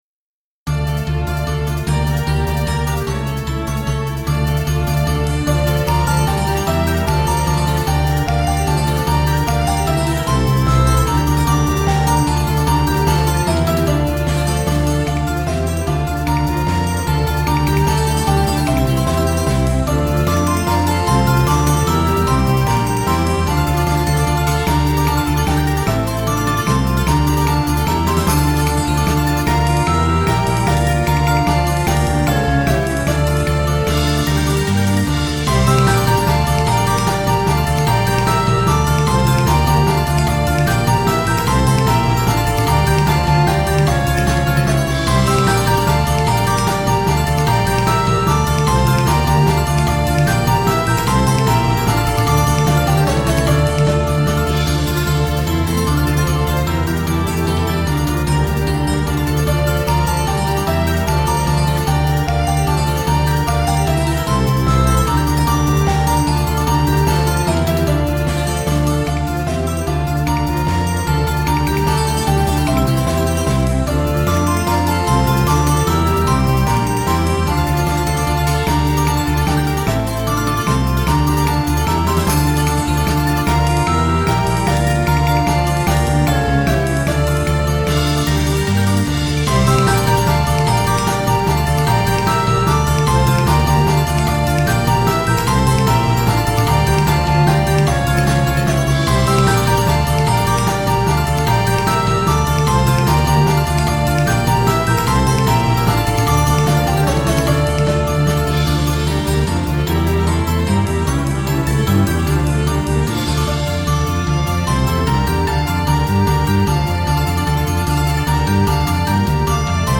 ニ短調